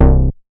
MoogAgress A.WAV